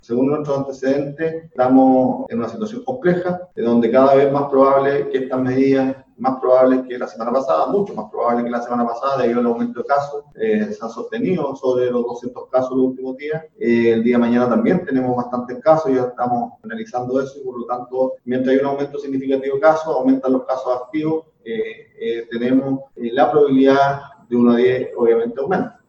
El seremi de Salud, Héctor Muñoz, reconoció que la situación cada día es más compleja teniendo en cuenta las cifras y que cada vez es más probable tener medidas más estrictas, más aún cuando los casos parece que no apuntan a la baja, y se espera otro alto número para este martes.
cua-salud-seremi-de-salud-1.mp3